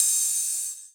DDK1 OPEN HAT 6.wav